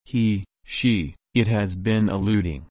/ɪˈluːd/